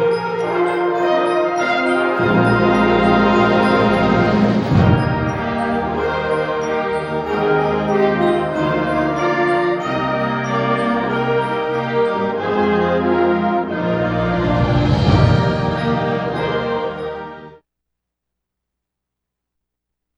Traditional carols and contemporary favorites were all part of the Emporia Municipal Band’s concert Sunday.
The Municipal Band filled the Emporia Granada Theatre with people and sound for its 16th annual Christmas concert, getting some help from the Jubilee Ringers — who had a brief performance before the official concert started.